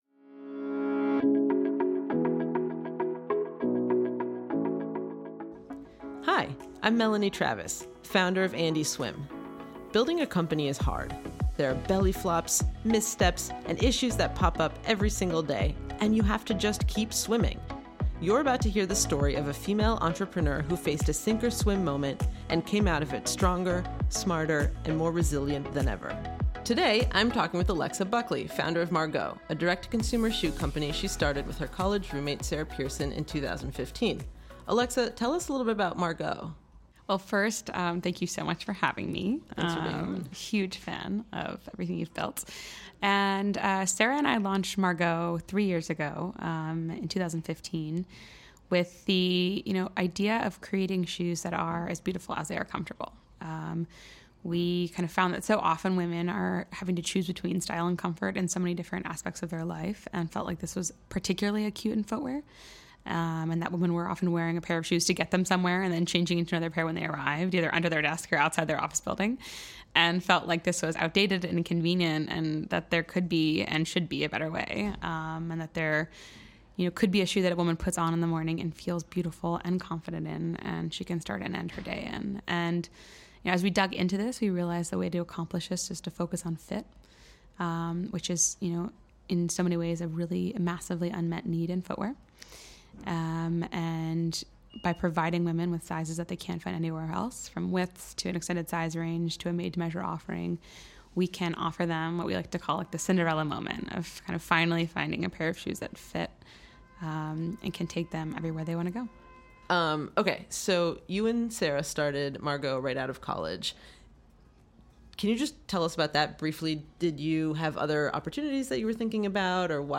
A series of intimate, honest conversations